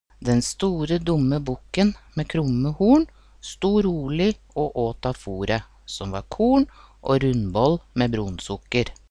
Ny side 1 Lytt til dialekteksemplene nedenfor.